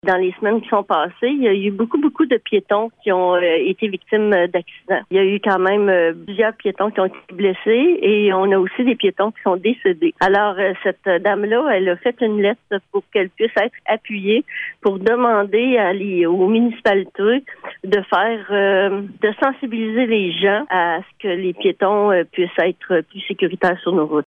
La mairesse de Grand-Remous, Jocelyne Lyrette, explique pourquoi le conseil a décidé d’appuyer la demande :